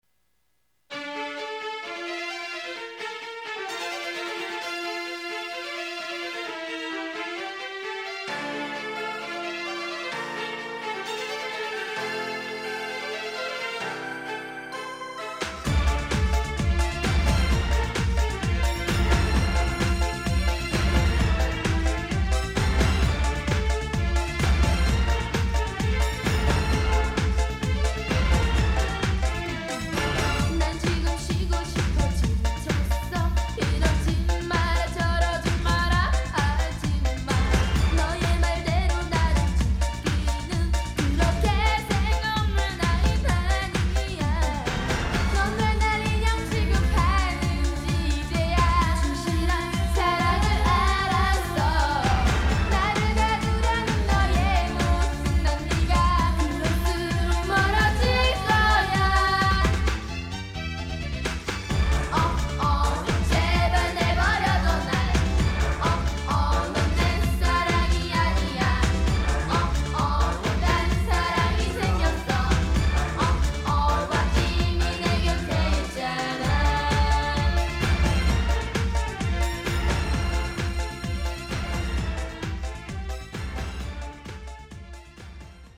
댄스풍 의 경쾌하고 빠른 음악 입니다.
MP3 압축, 16비트, 1 28K, 스테레오..
이 곡은 스페셜팩의 오리지날 사운드 트랙에 들어가게 되는 보컬곡 입니다.